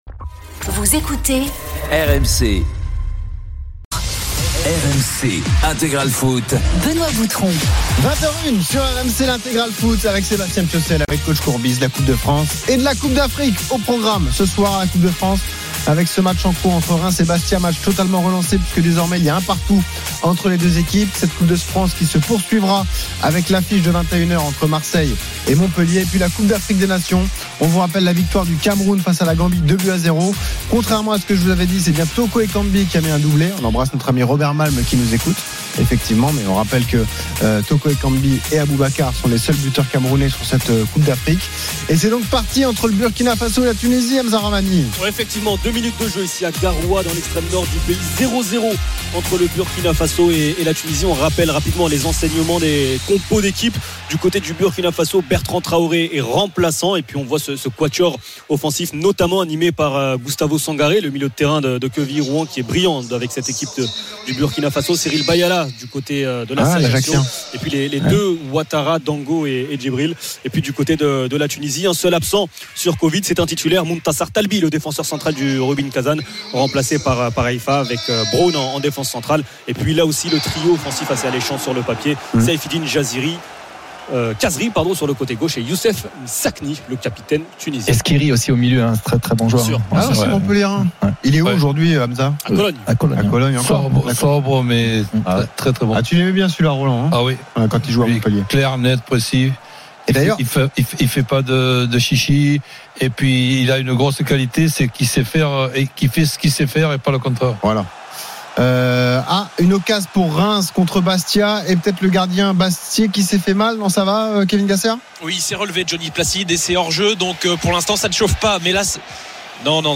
Tous les matchs en intégralité, sur RMC la radio du Sport.